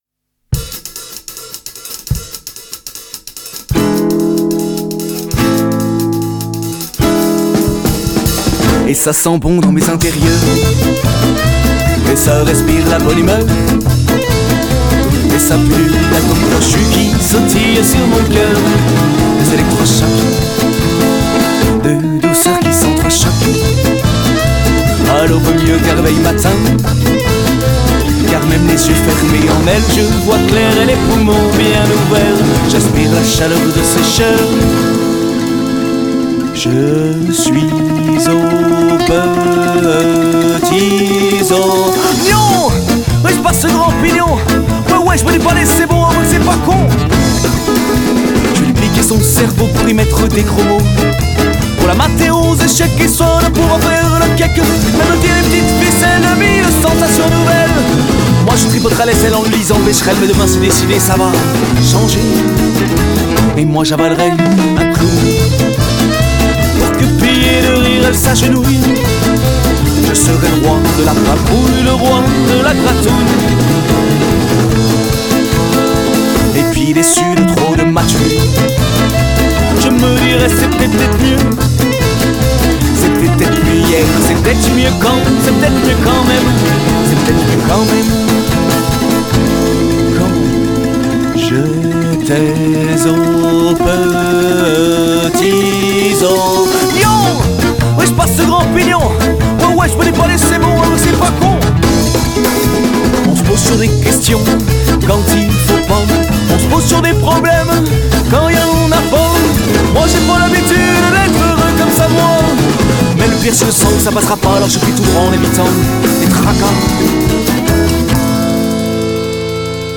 au casque les sifflantes de la voix sont un peu soulante.